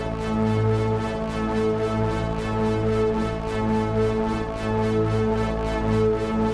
Index of /musicradar/dystopian-drone-samples/Tempo Loops/110bpm
DD_TempoDroneD_110-A.wav